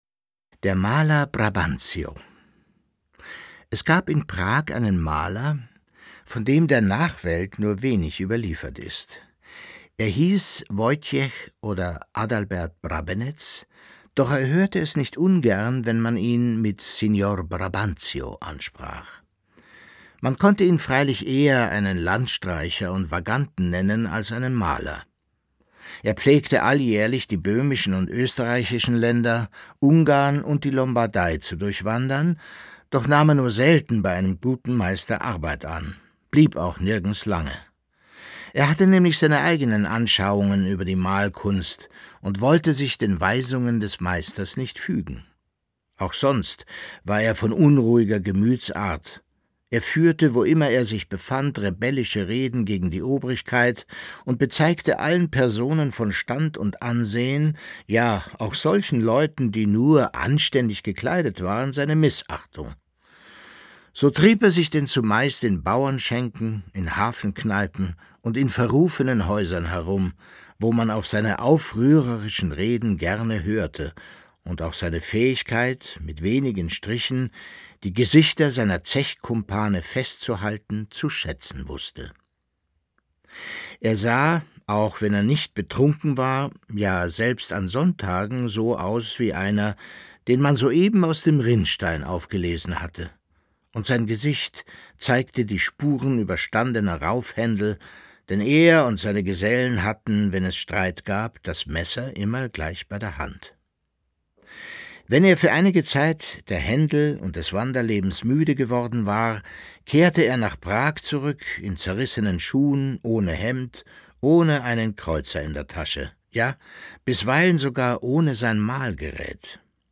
Leo Perutz: Nachts unter der steinernen Brücke (14/25) ~ Lesungen Podcast